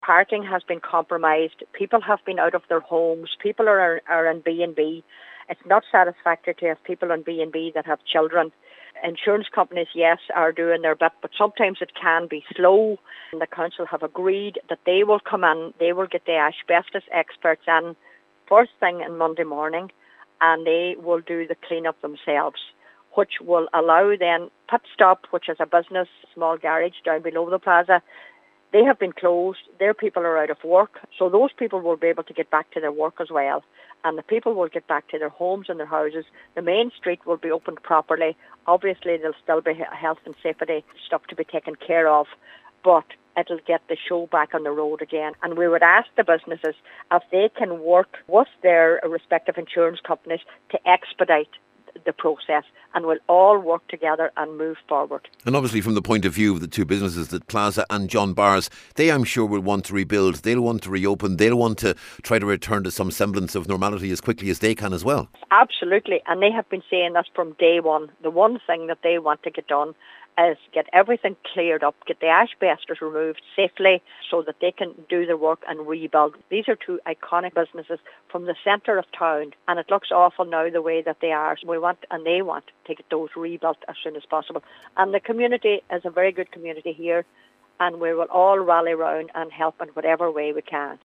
Cllr Rena Donaghey says it’s the right thing to do……………..